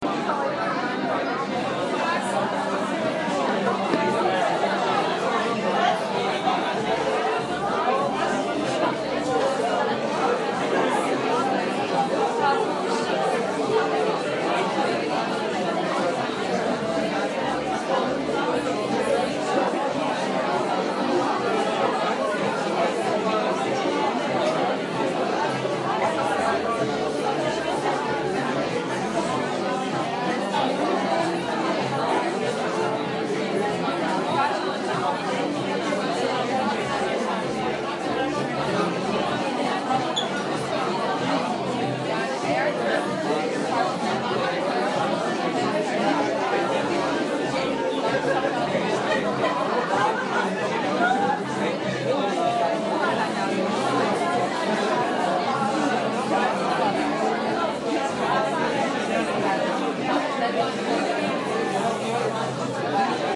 描述：烟花的未加工的音频显示在Godalming，英国。我用Zoom H1和Zoom H4n Pro同时录制了这个事件来比较质量。令人讨厌的是，组织者还在活动期间抨击了音乐，因此安静的时刻被遥远的，虽然模糊不清的音乐所污染。
声音是在2017年11月3日使用“H4n Pro Zoom录音机”录制的。